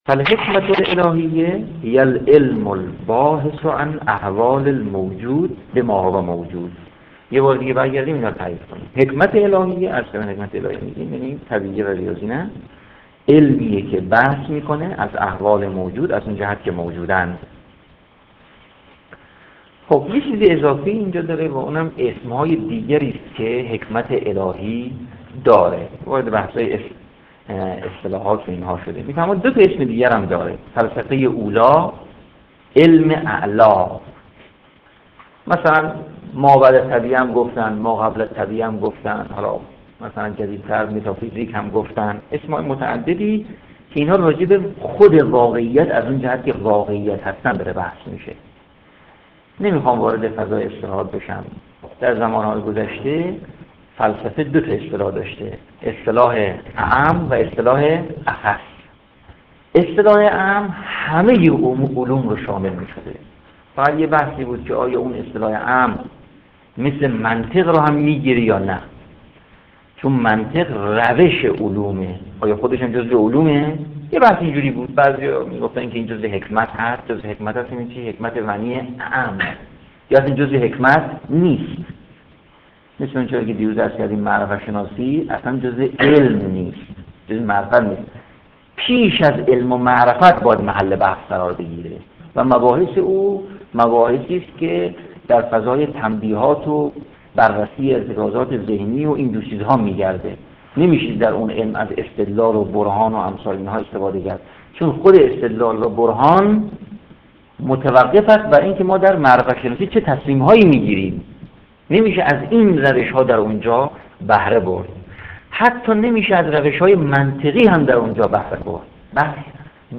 تدریس بدایه الحکمه